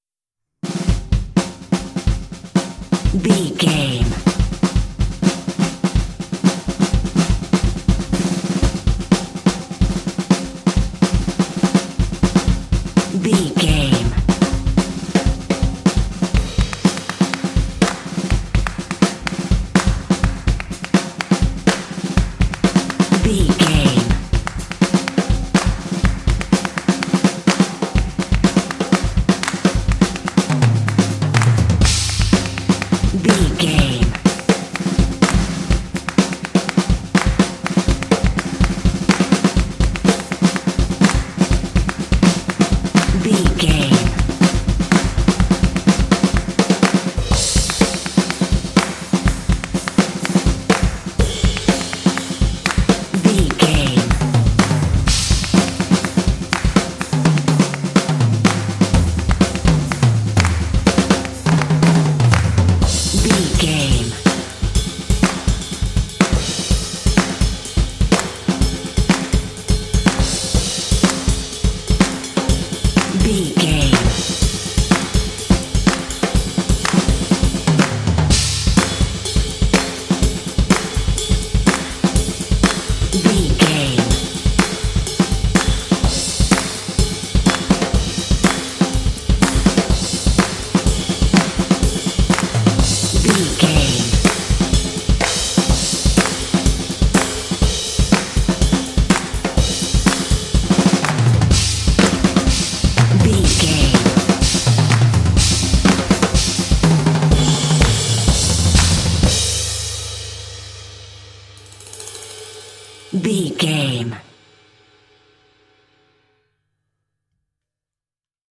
Epic / Action
Fast paced
Aeolian/Minor
drumline
contemporary underscore